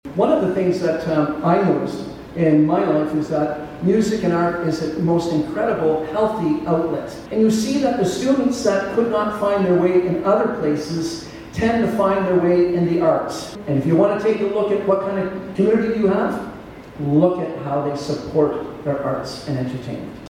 VALLEY VOTES: Algonquin-Renfrew-Pembroke federal candidates debate at Festival Hall L’Equinox a success